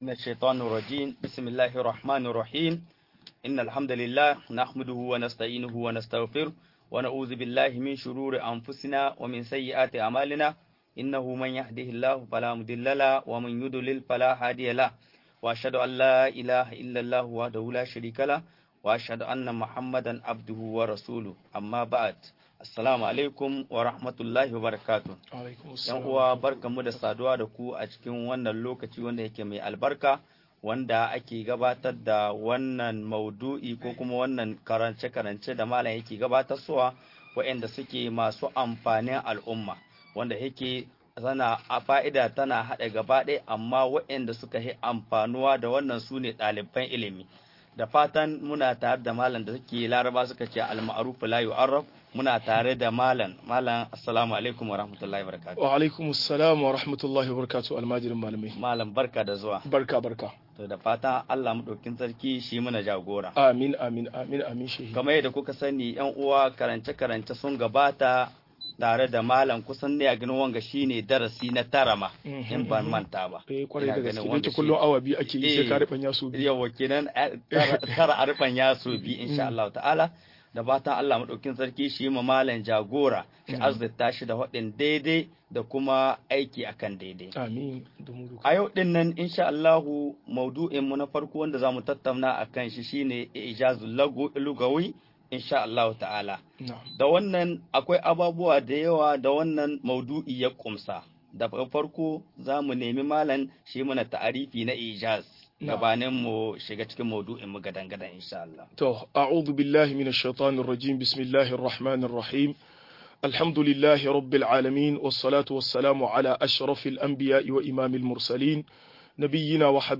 Mu'ujizar lugar Alkur'ani - MUHADARA